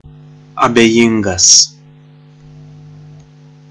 Ääntäminen
US : IPA : [ɪn.ˈdɪ.frənt]